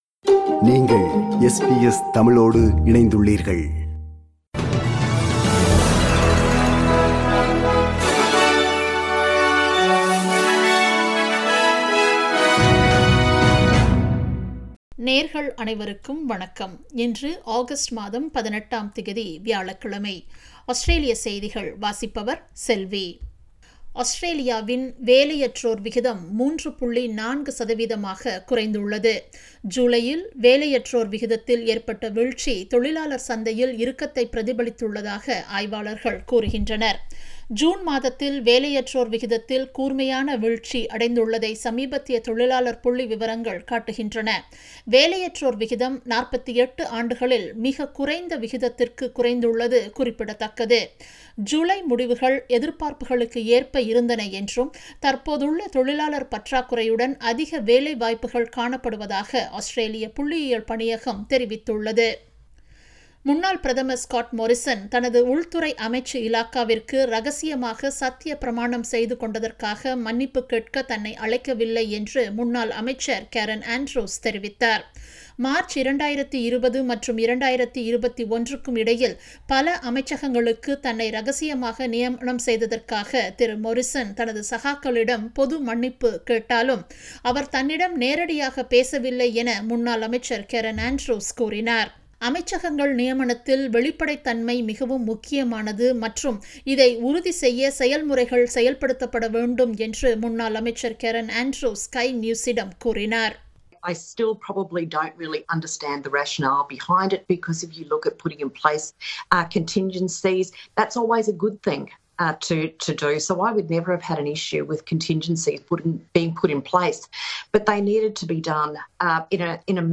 Australian news bulletin for Thursday 18 Aug 2022.